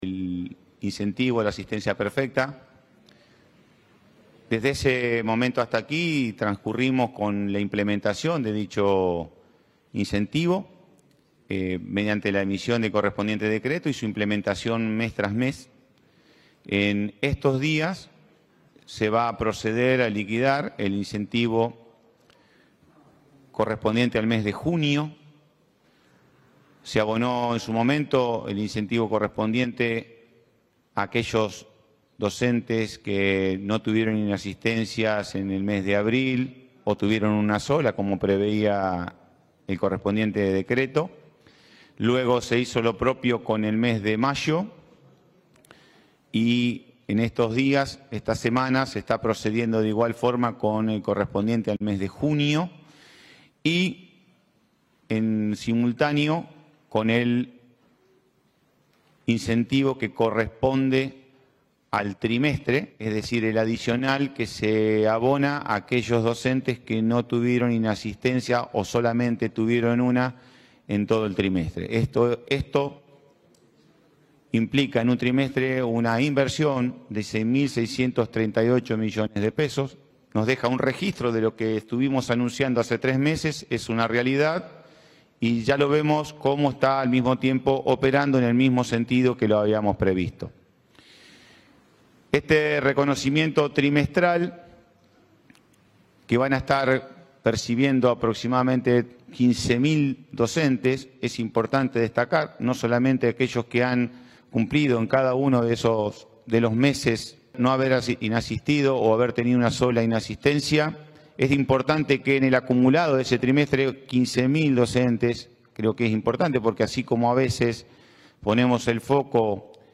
Pablo Olivares, ministro de Economía - José Goity, ministro de Educación